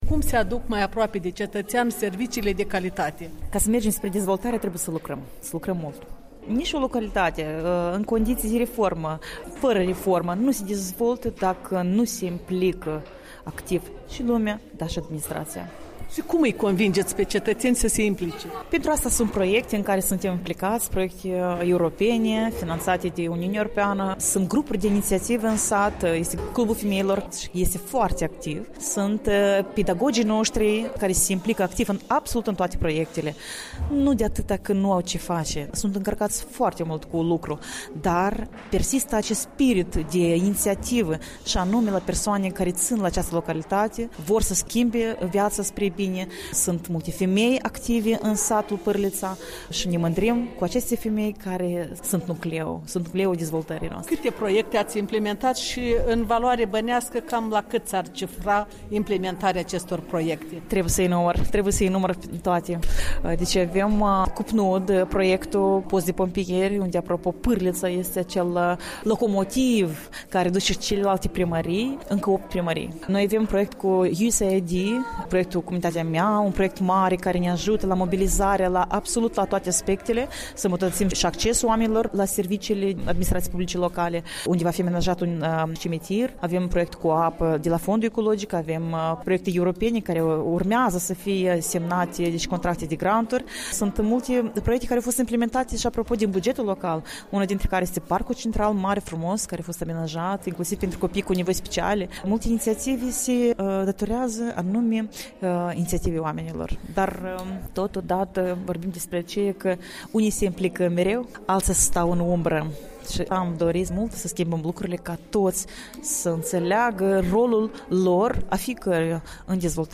O convorbite cu Liudmila Nistor, care conduce primăria comunei Pârlița, una dintre cele opt femei primar din raionul Ungheni.